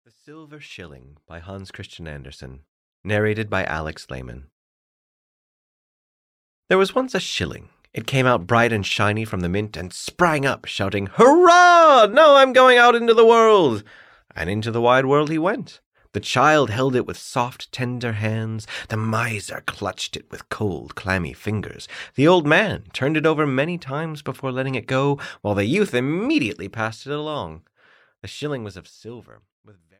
The Silver Shilling (EN) audiokniha
Ukázka z knihy